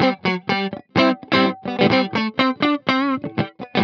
24 GuitarFunky Loop A.wav